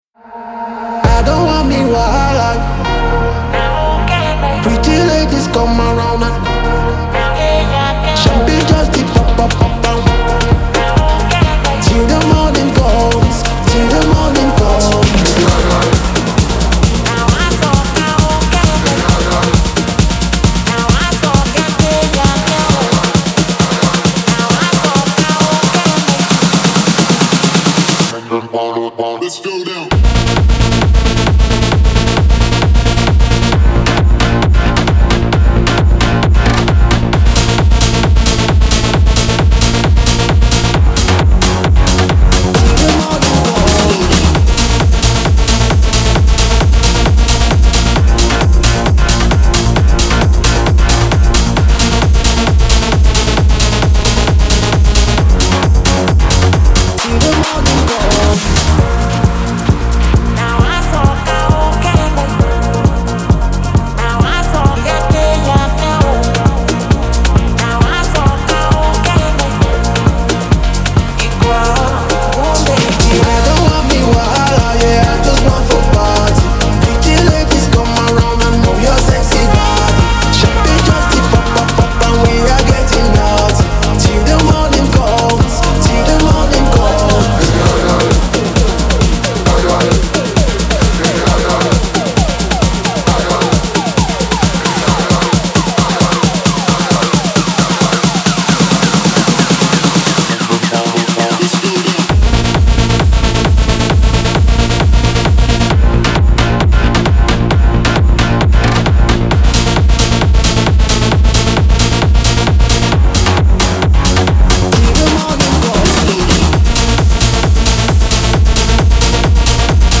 • Жанр: EDM, House